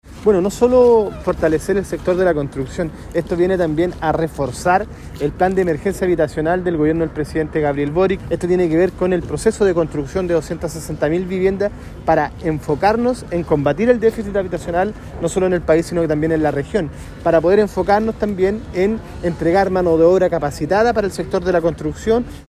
DPR-Capacitacion-Construccion_Delegado-Regional.mp3